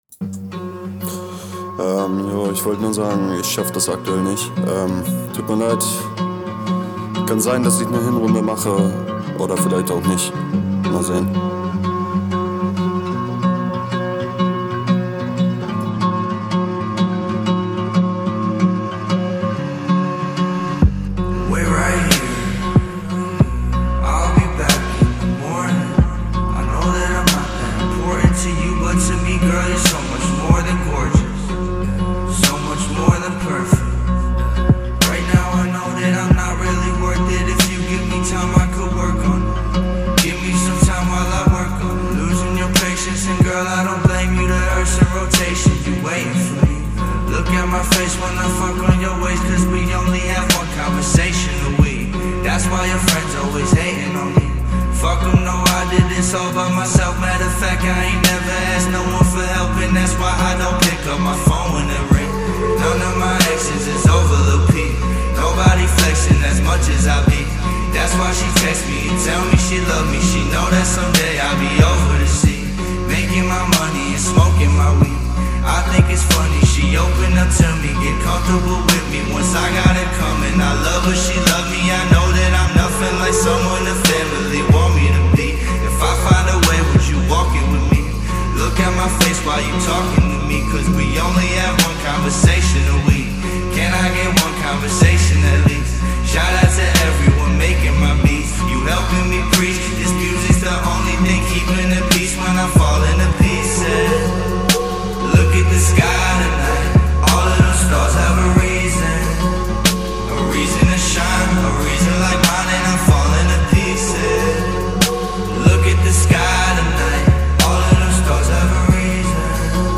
Deine Vocals im Intro sind etwas laut, man hört ein bisschen Rauschen.